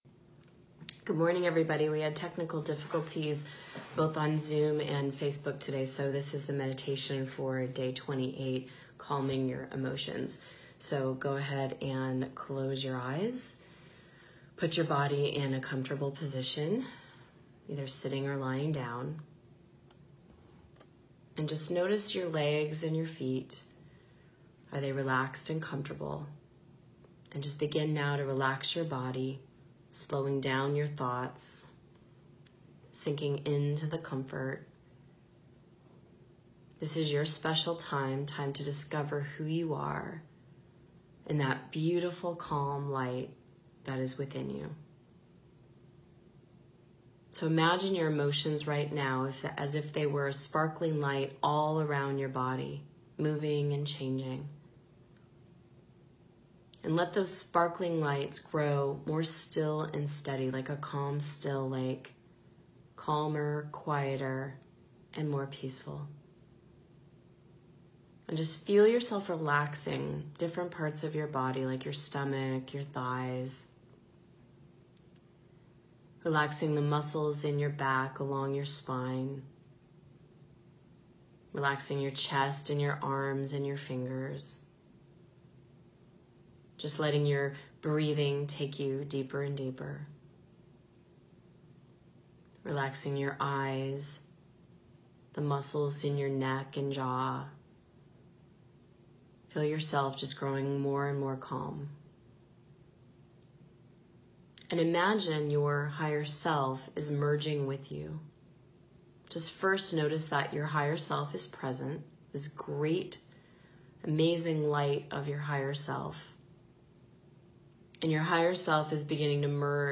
Calming Your Emotions Meditation